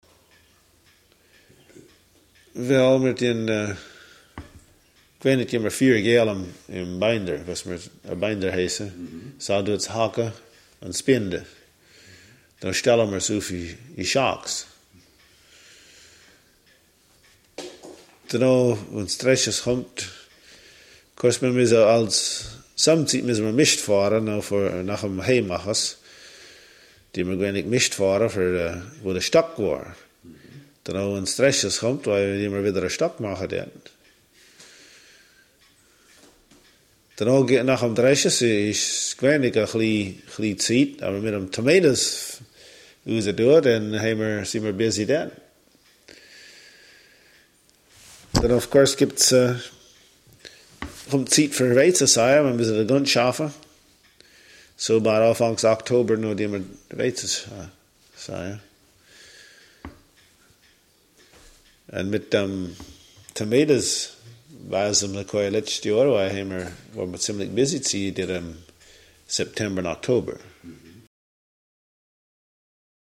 features a male speaker of Amish Swiss German
in Allen County, Indiana, USA in 1984.
Here is a transcription and English translation of the interview: